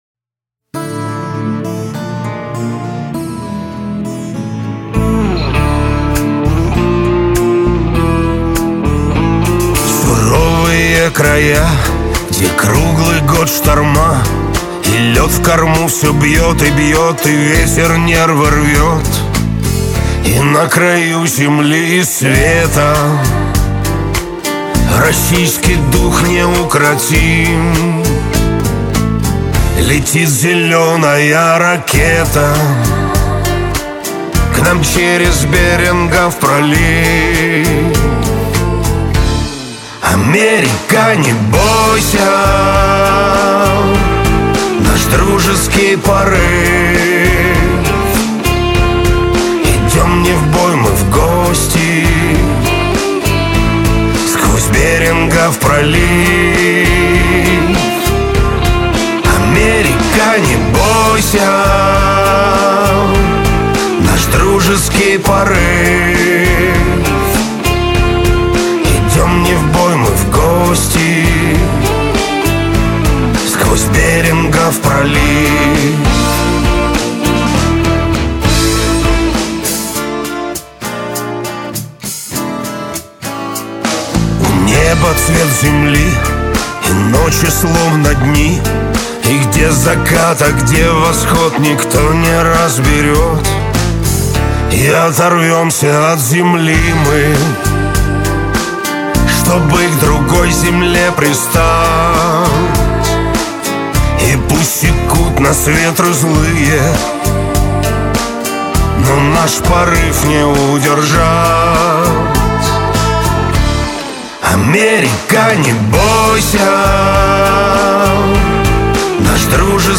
Студийка..............